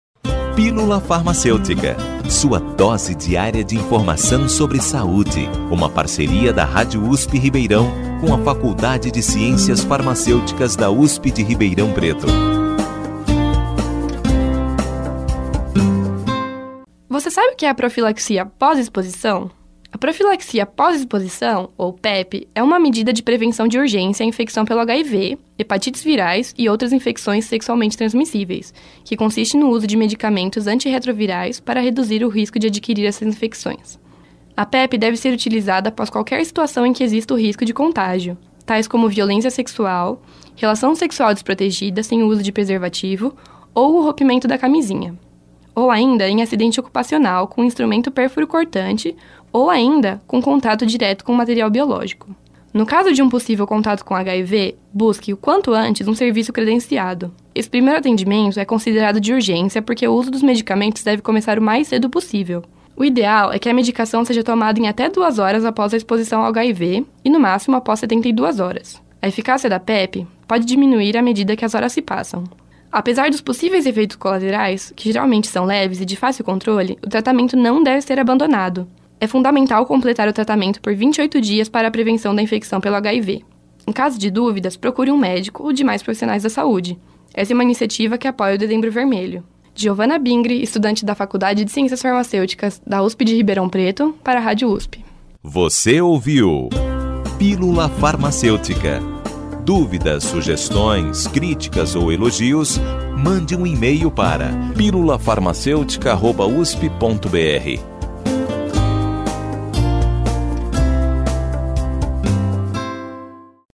O boletim Pílula Farmacêutica é apresentado pelos alunos de graduação da Faculdade de Ciências Farmacêuticas de Ribeirão Preto (FCFRP) da USP